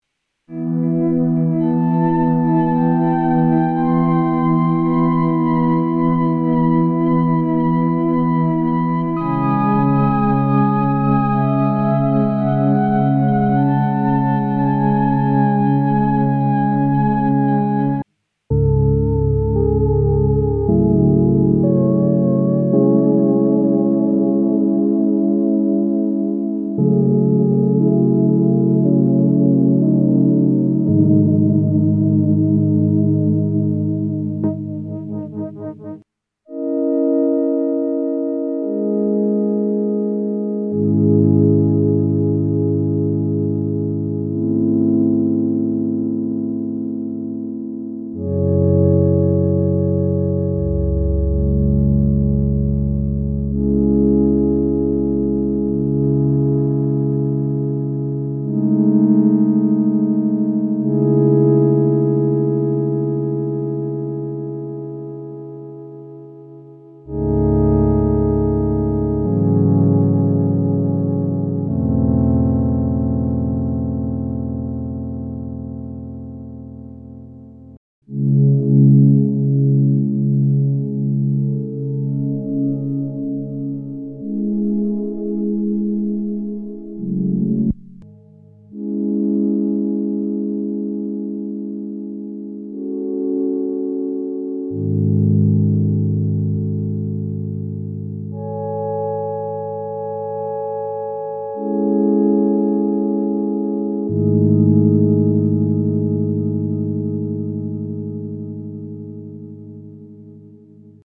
Moog Muse - 8 Voice polyphonic Synthesizer V1.4
Jo, von Haus aus ist es eher bratzig, das stimmt, aber sanft geht: Danke fuer das Beispiel, aber ich moechte mal sagen, dass gerade dieses meine Meinung bestätigt, dass es oben herum immer durchschimmert.